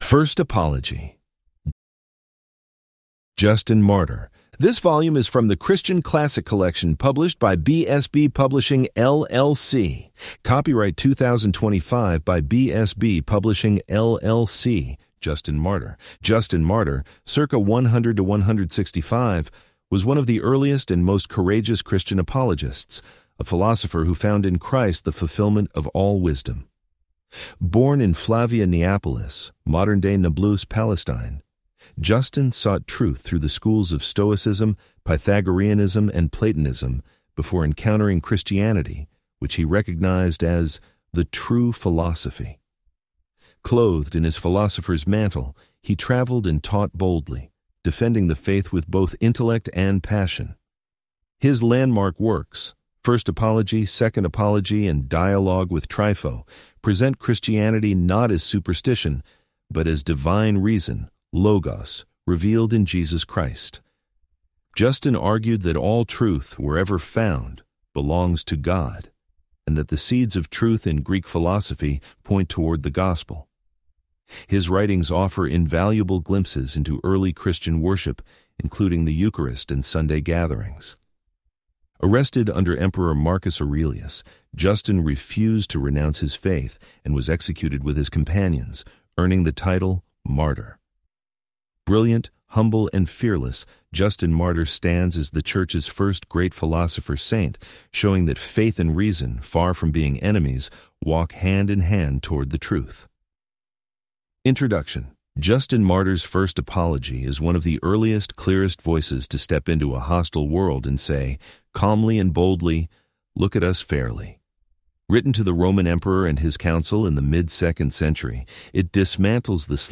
Complete Audiobook Play Download Individual Sections Listening Tips Download the MP3 files and play them using the default audio player on your phone or computer.